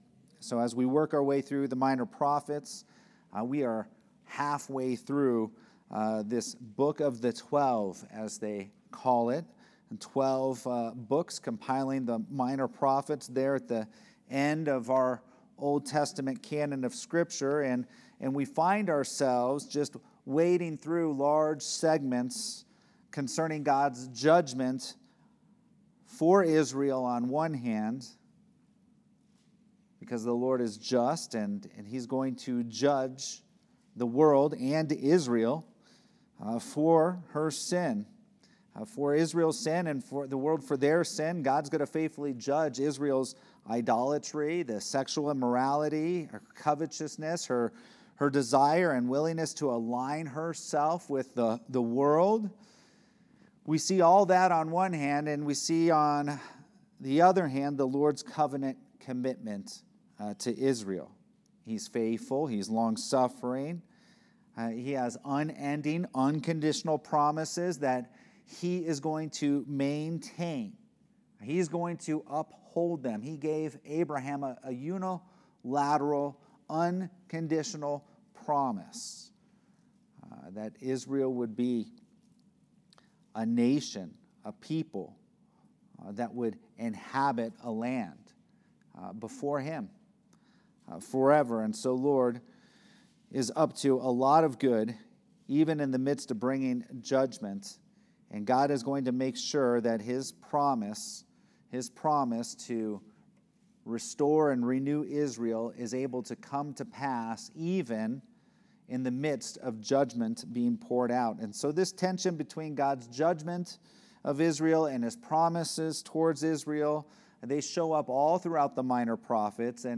Sermons | Redemption Hill Church